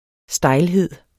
Udtale [ ˈsdɑjlˌheðˀ ]